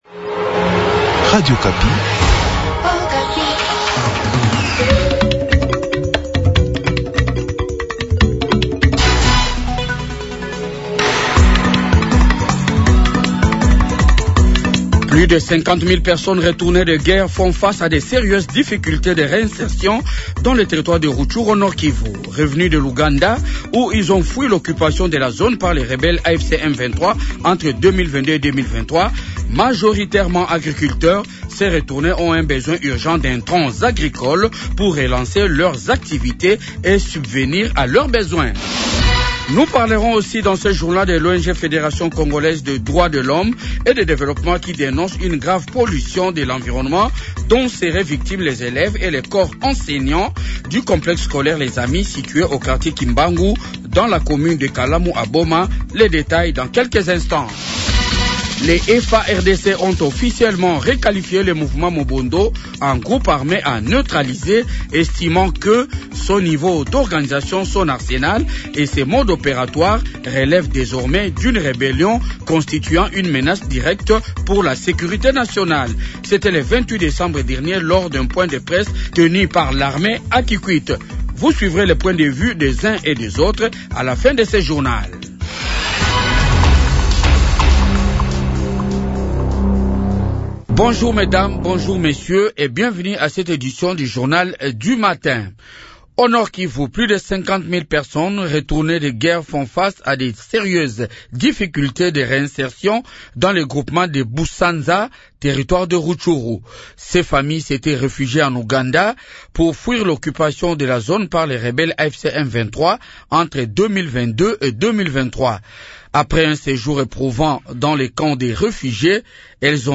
Journal du matin 7h